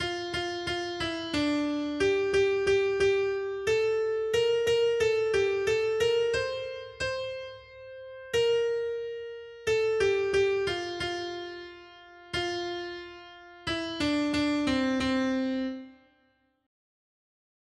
Noty Štítky, zpěvníky ol601.pdf responsoriální žalm Žaltář (Olejník) 601 Skrýt akordy R: Pamatuj na nás, Hospodine, pro náklonnost k svému lidu. 1.